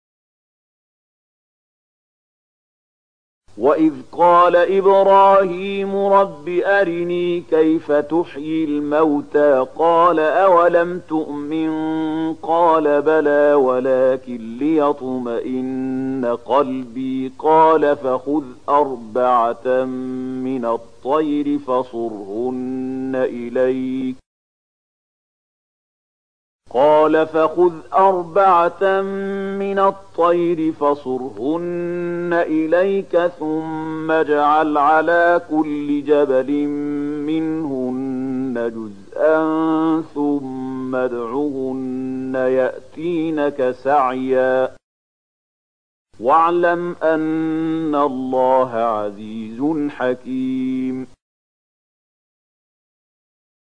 002260 Surat Al-Baqarah ayat 260 dengan bacaan murattal Syaikh Mahmud Khalilil Hushariy: